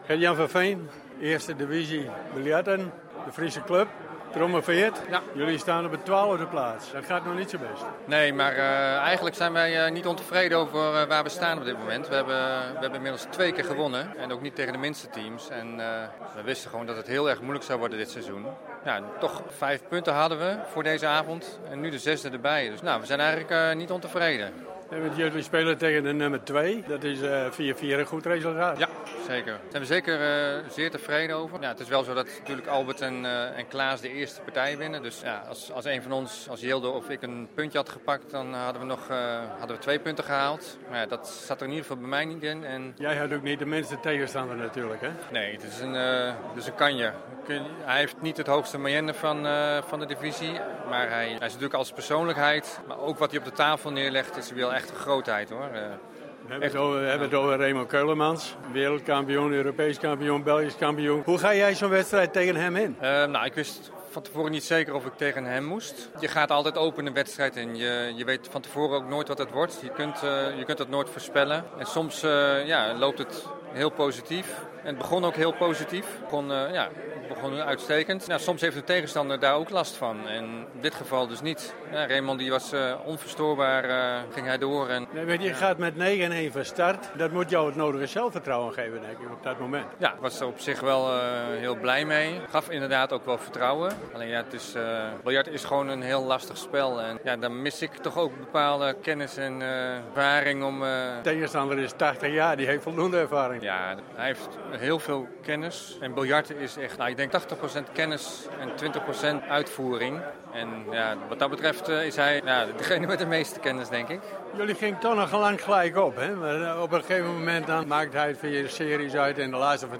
Een gesprekje met Raymond Ceulemans: 35 Keer wereldkampioen, 48 keer Europees kampioen en 61 keer kampioen van België.
Underweis - Interviews - Sport - Onderweg